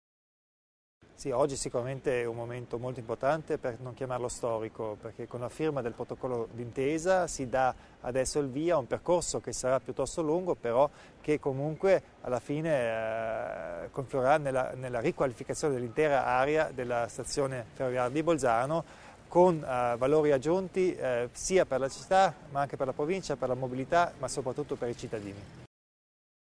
Il Presidente Kompatscher illustra l'importanza del progetto per l'areale ferroviario